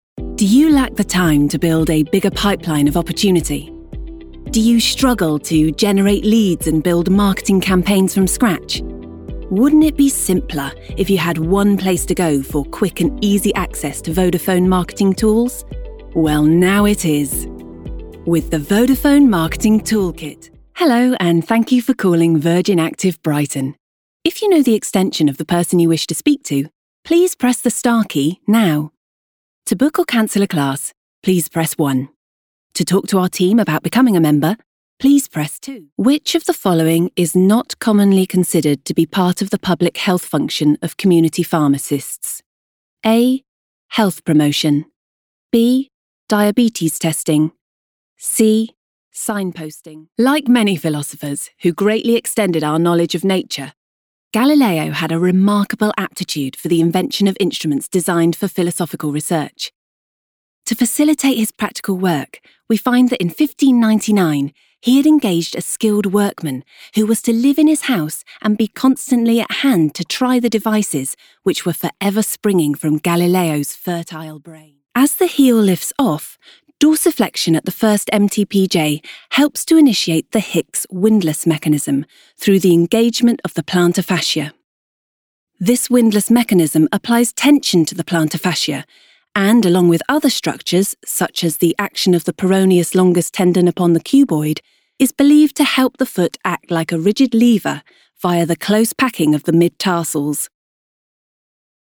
Female
English (British)
I have a voice that is bright, trustworthy and playful. It is warm and engaging and when telling the story of your brand or character, you can count on me to bring it to life. My natural British voice is lower than average and of a southern inclination. My accent is a soft RP, though I can stretch it to sound posh if you need me to!
Corporate